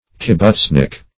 (k[i^]b*b[oo^]ts"n[i^]k; k[i^]b*b[=oo]ts"n[i^]k)